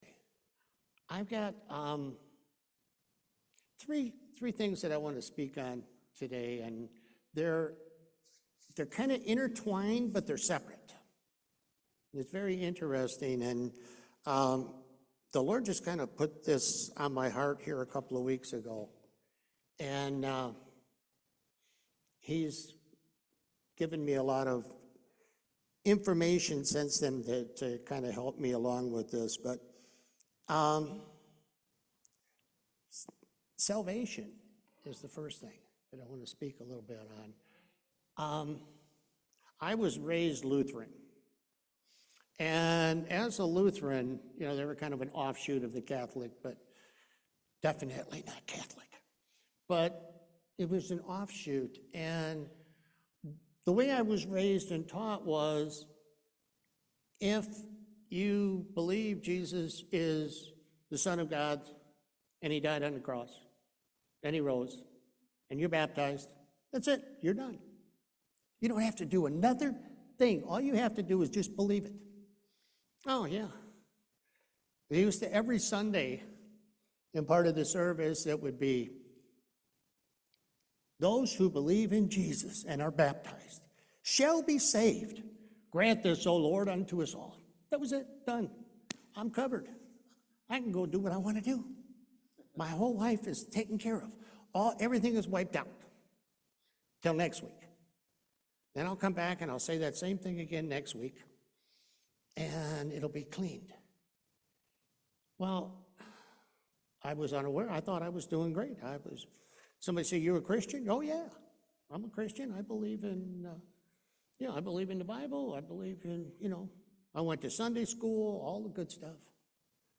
James 2:17-18 Service Type: Main Service Salvation is a gift from God.